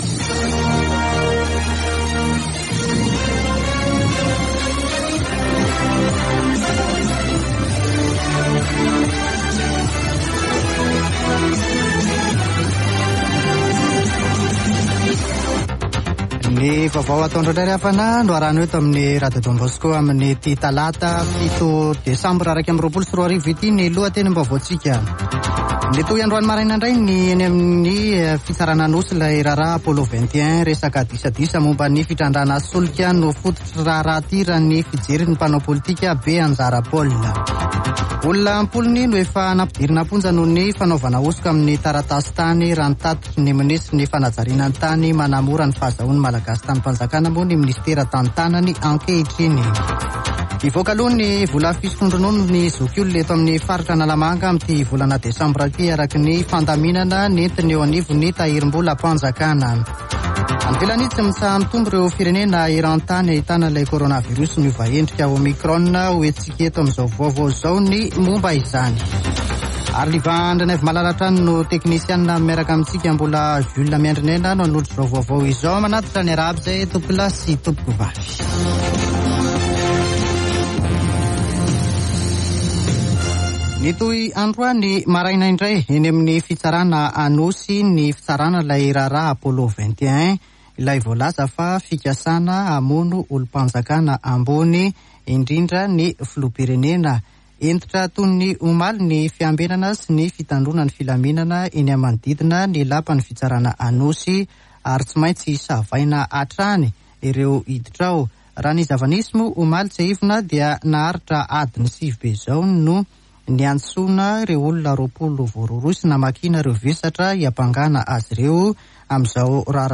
[Vaovao antoandro] Talata 07 desambra 2021